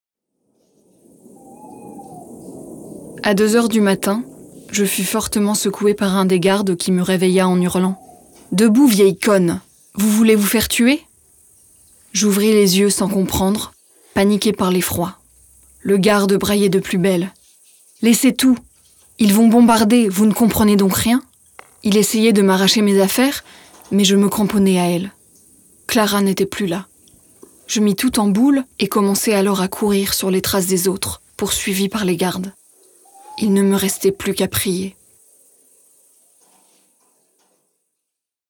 livre audio
Voix off
6 - 40 ans - Soprano